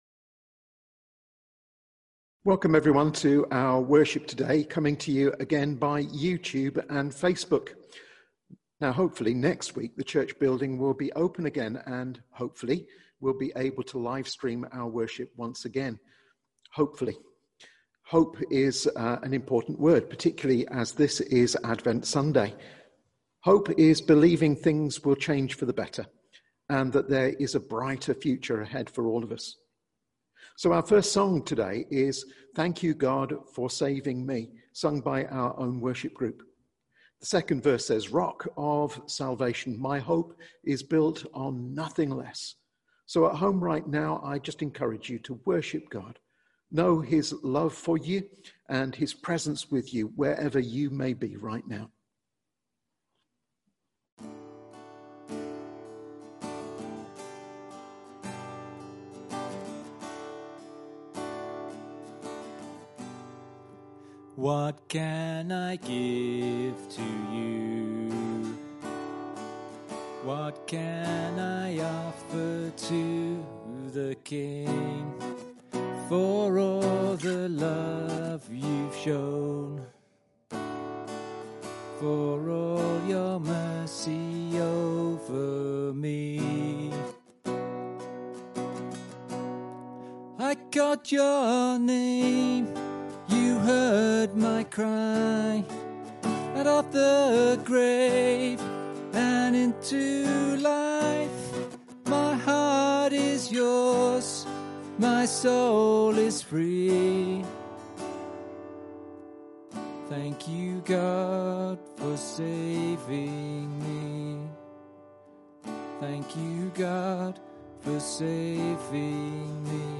A message from the series
From Service: "10.30am Service"